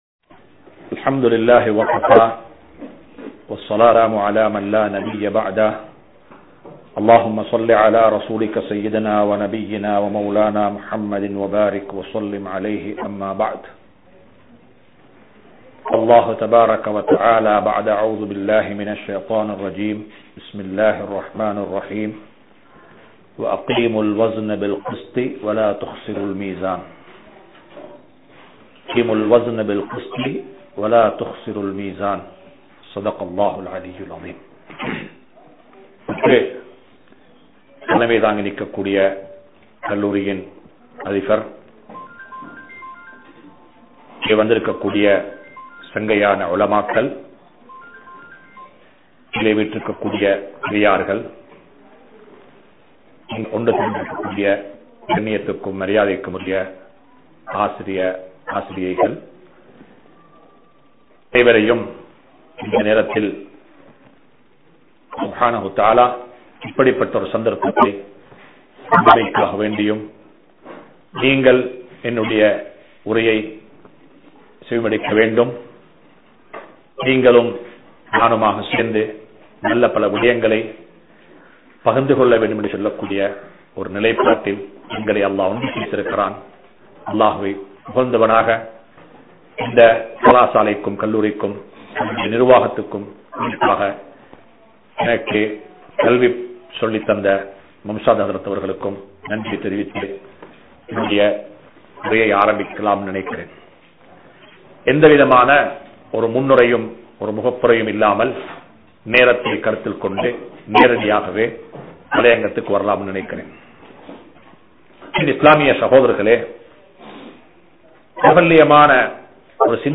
The Importance of Education | Audio Bayans | All Ceylon Muslim Youth Community | Addalaichenai
Welamboda Muslim Maha Vidyalayam